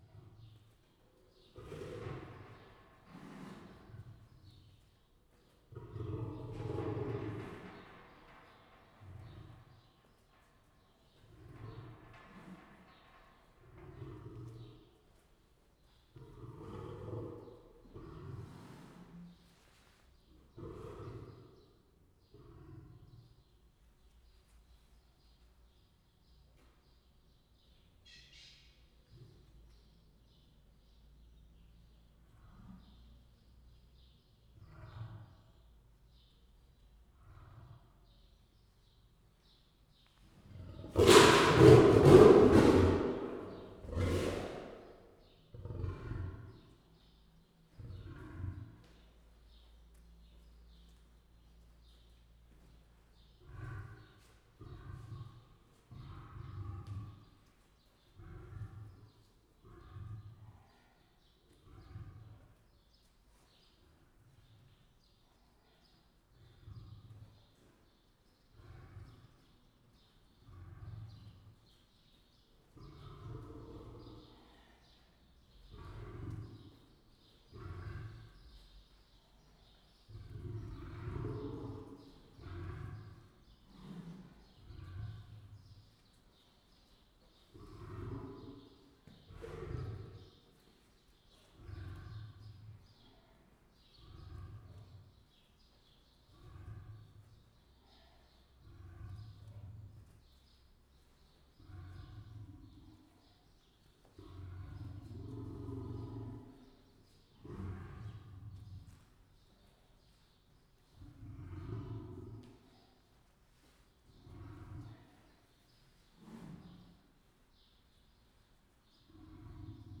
amennyibenkozelitenekhozzátamad_egyebkentbekesenhorog03.13.WAV